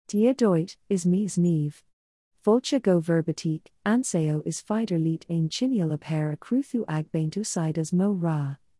Niamh — Female Irish English AI voice
Niamh is a female AI voice for Irish English.
Voice sample
Female
Niamh delivers clear pronunciation with authentic Irish English intonation, making your content sound professionally produced.